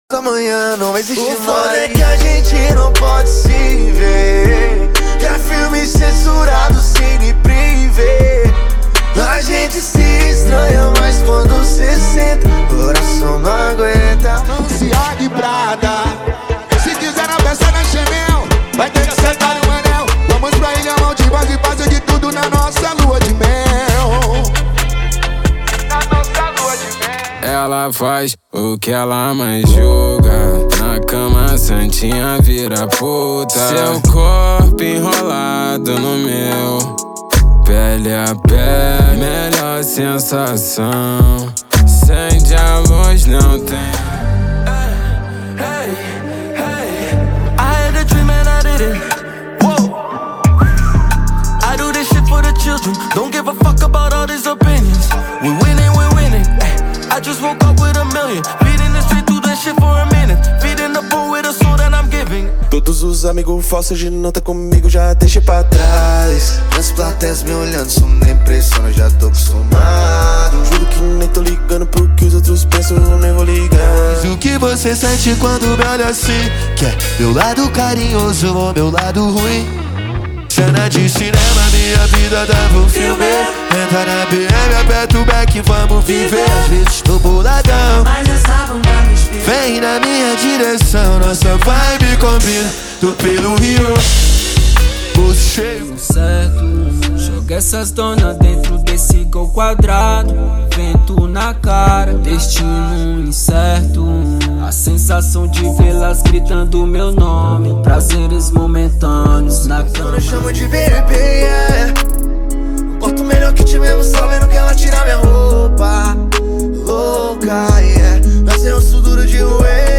Descubra os Melhores Trap & Hip Hop do momento!!!
Sem Vinhetas
Em Alta Qualidade